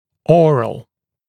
[‘ɔːrəl][‘о:рэл]ротовой, оральный